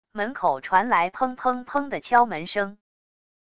门口传来砰砰砰的敲门声wav音频